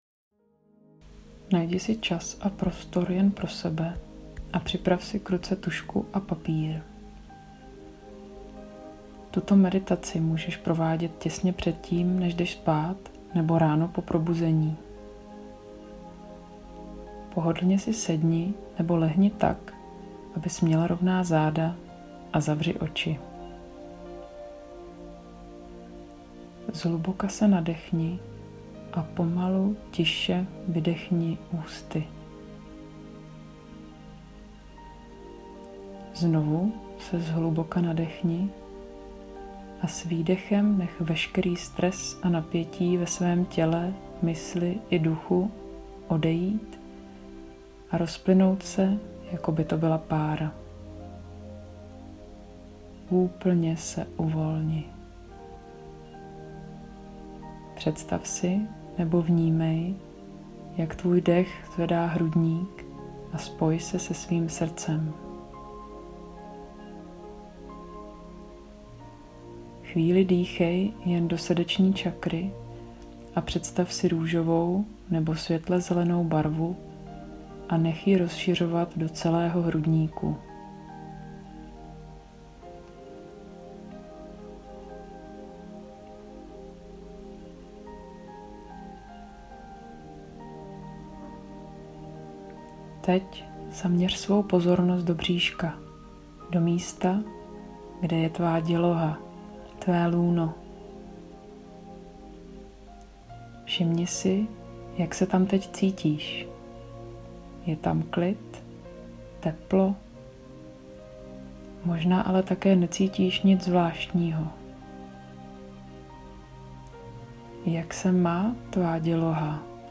Meditace lůno